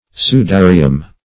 Search Result for " sudarium" : The Collaborative International Dictionary of English v.0.48: Sudarium \Su*da"ri*um\, n. [L., a handkerchief.]